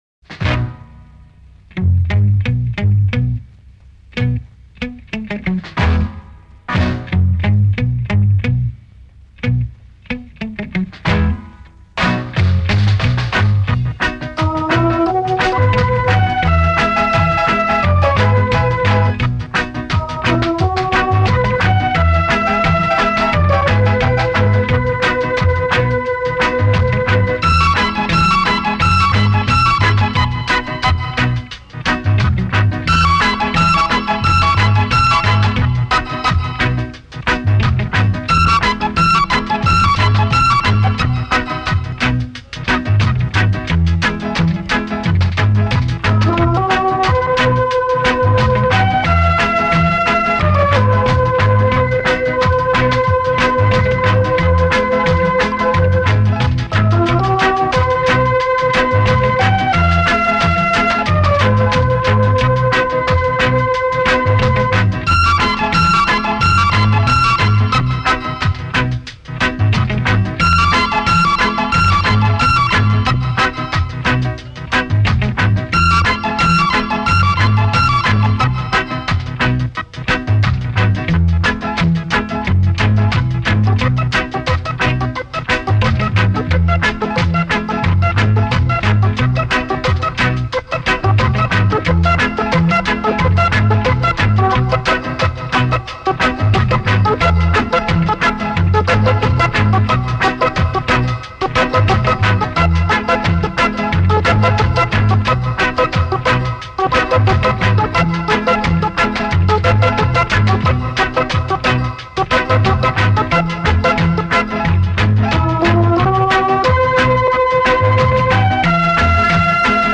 it was a quirky instrumental track